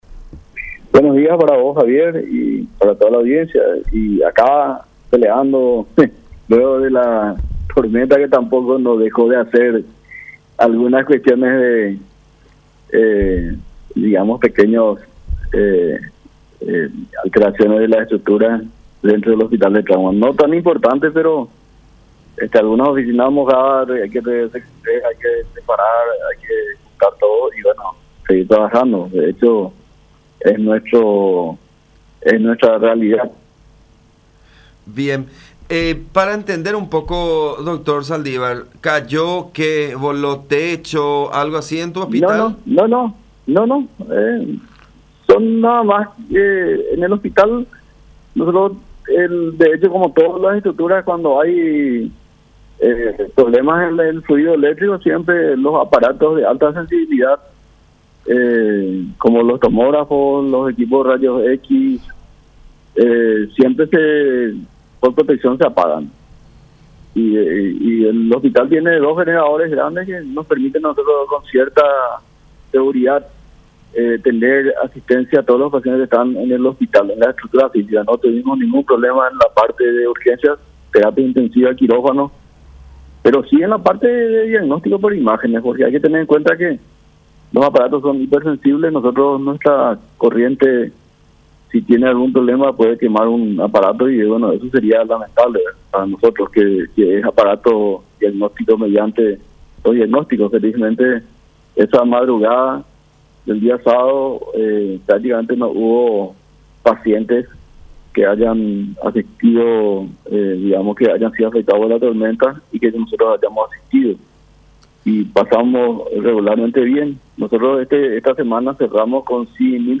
“Los pacientes atendidos viernes, sábado y domingo en su mayoría un 70% estuvieron consumiendo algún tipo de estupefaciente o  alcohol” expresó en contacto con La Unión R800 AM.